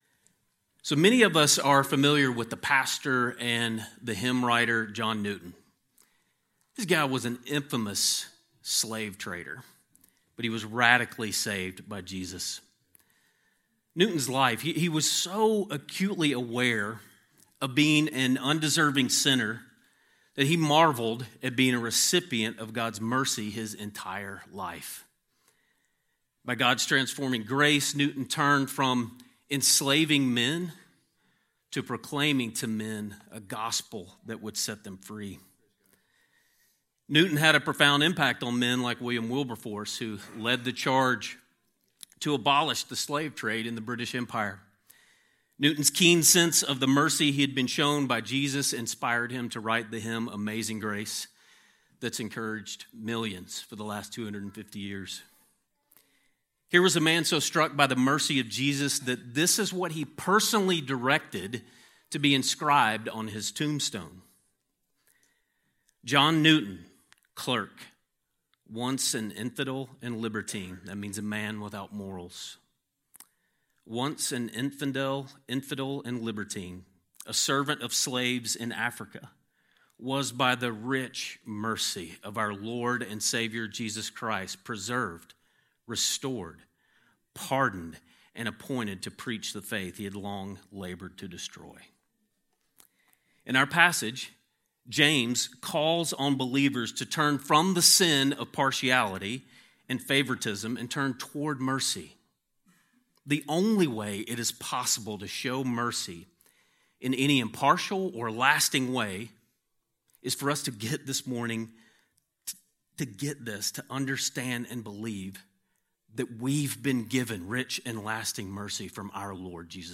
A sermon on James 2:8-13